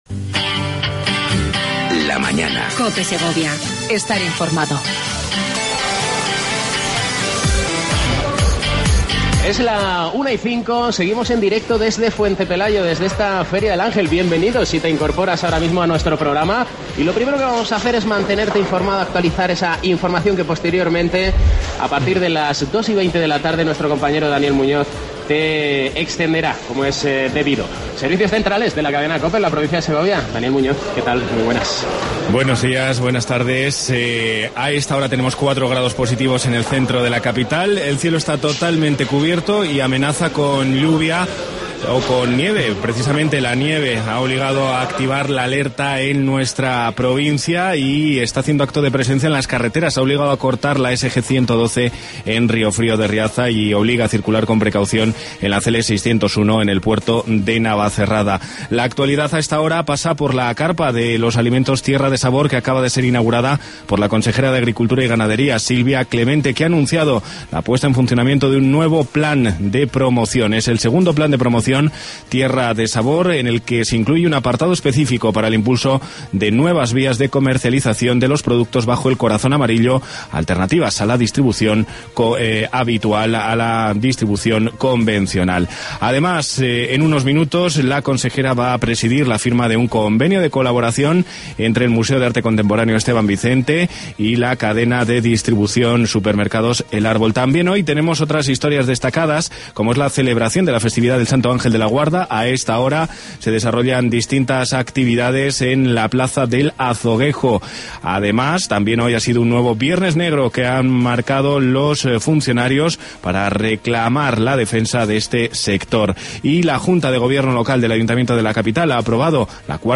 AUDIO: Edición Especial desde la Feria del Ángel de Fuentepelayo.